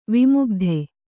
♪ vimugdhe